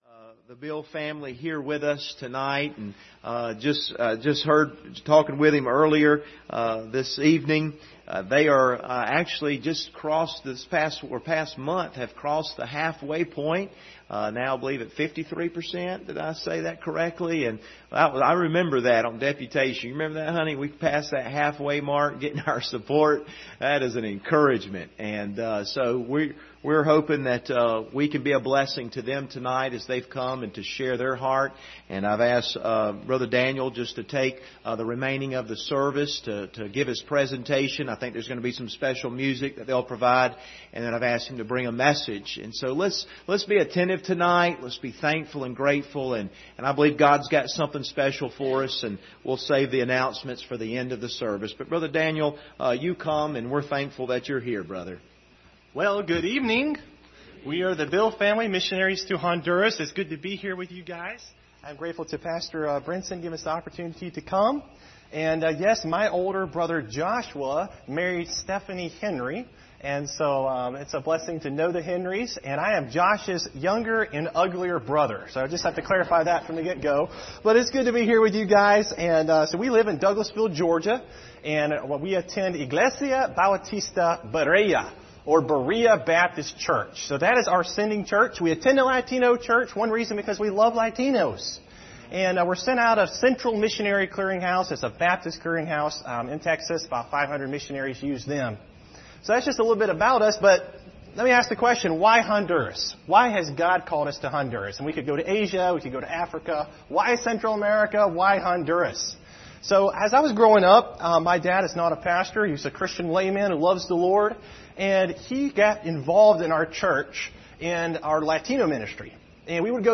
Passage: John 12:20-26 Service Type: Sunday Evening « The Storms That Come When Someone Sails Out Of The Will Of God Quieting a Noisy Soul Session 11 Part 2